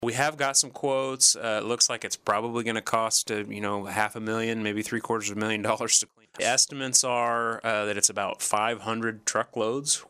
Interim City Manager Jacob Wood joined in on the KSAL Morning News Extra with a look at a range of topics including the 100-yard long pile of driftwood that is now wedged in the river.